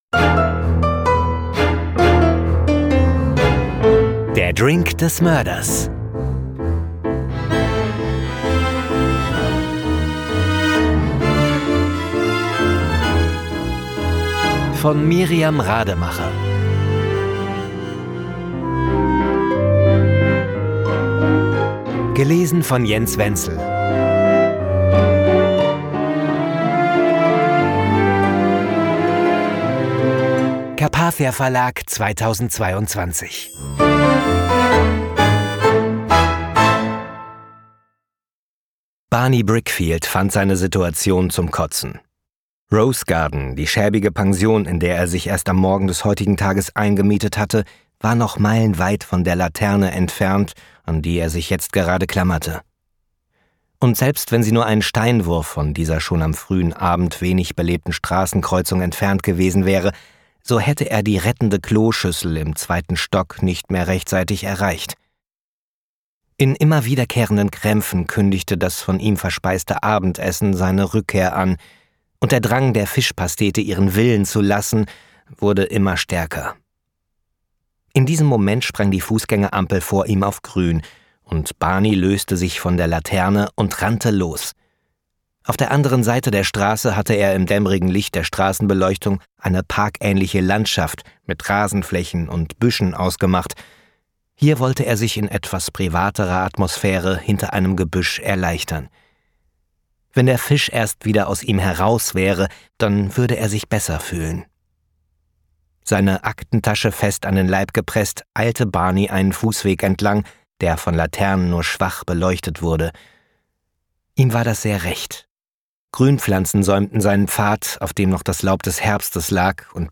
Der Drink des Mörders (Hörbuch)